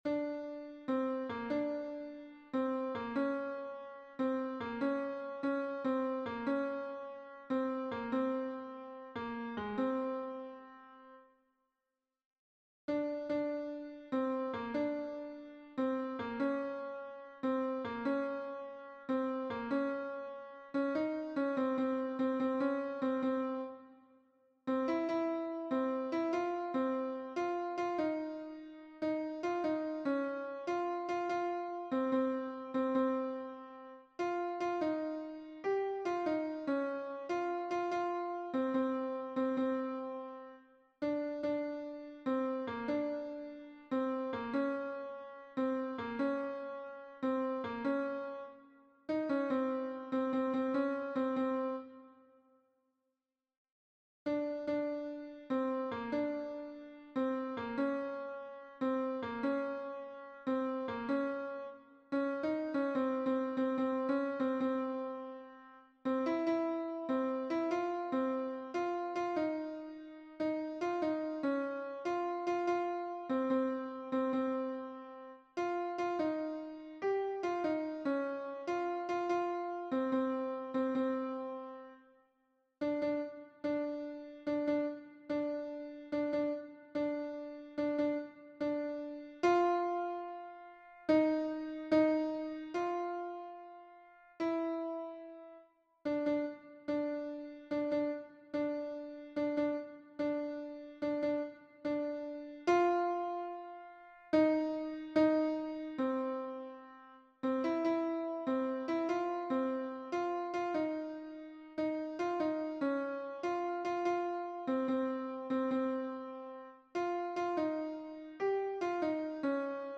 MP3 version piano
Alto